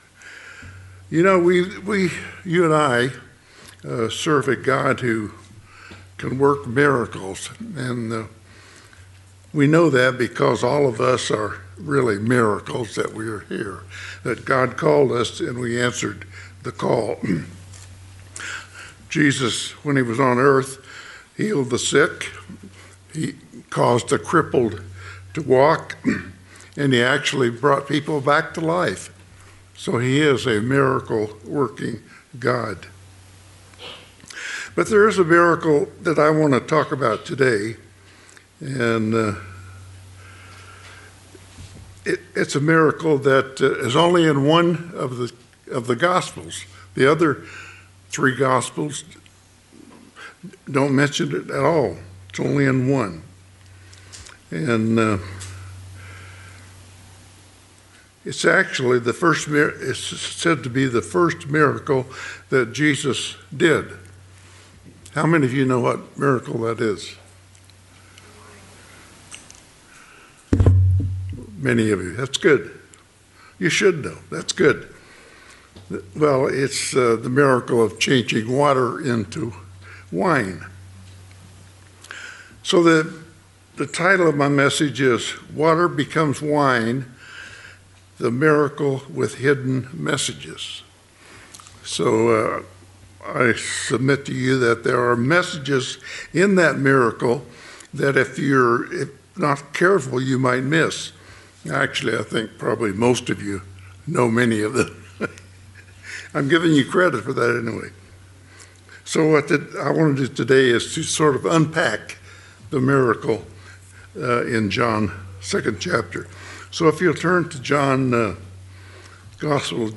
Given in San Diego, CA Redlands, CA Las Vegas, NV